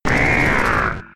Cri d'Abo K.O. dans Pokémon X et Y.